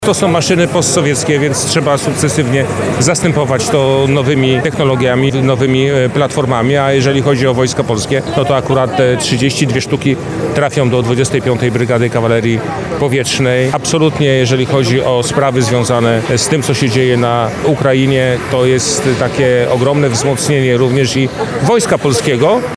Mamy wiele wysłużonych, różnych „MI-ileś”- wskazuje wiceminister obrony narodowej Paweł Bejda